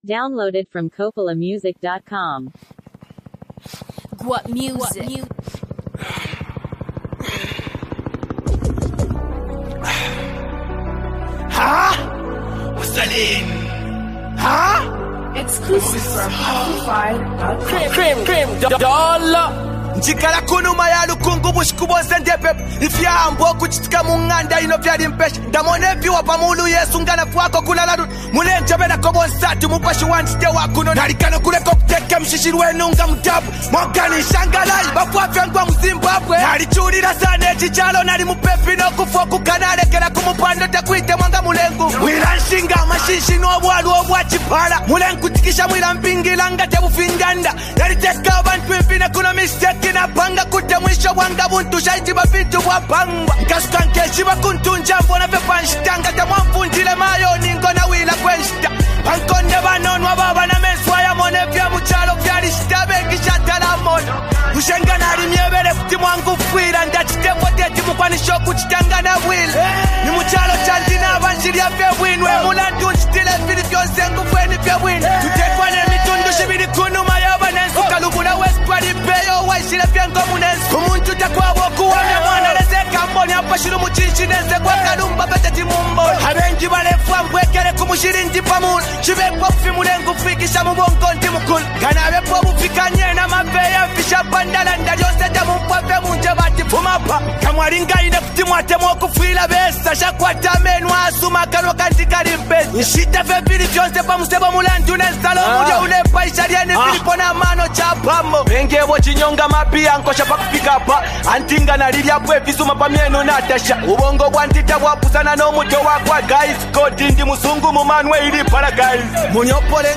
a reflective and emotionally charged song